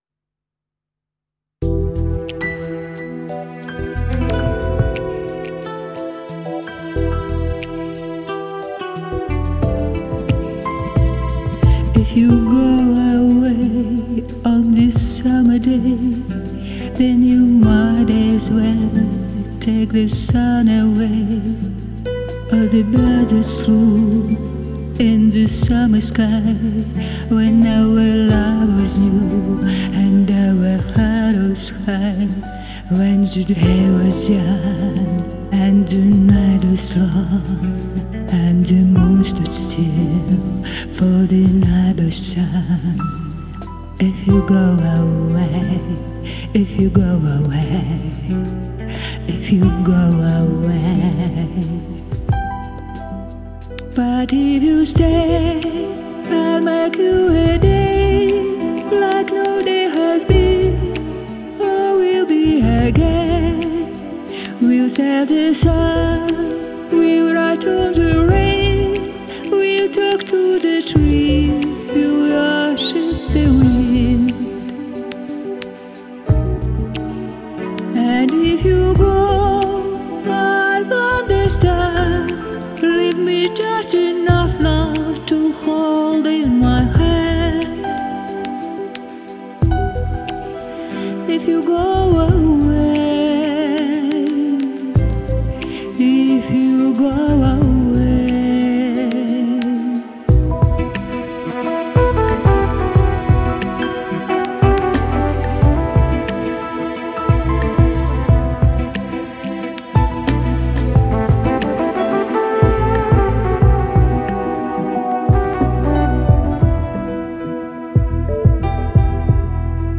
Джаз тянет, как родной.